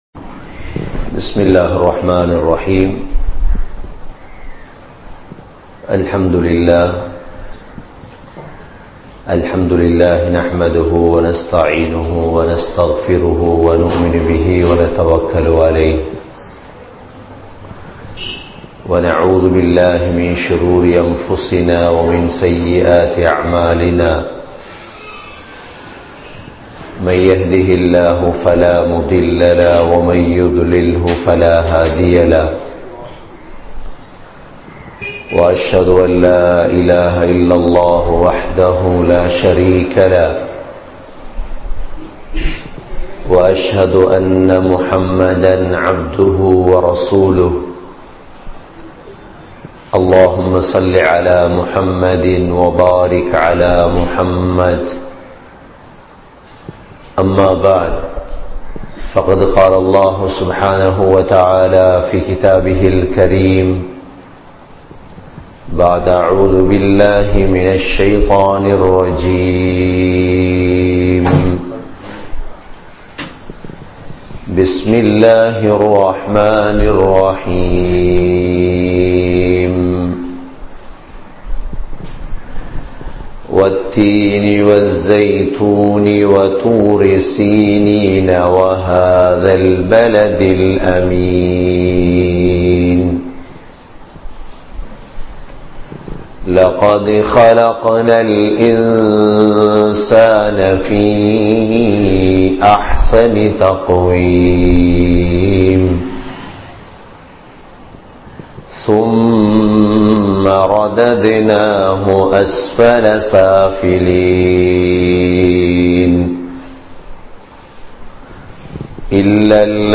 Allah`vai Maranthu Vidaatheerhal (அல்லாஹ்வை மறந்து விடாதீர்கள்) | Audio Bayans | All Ceylon Muslim Youth Community | Addalaichenai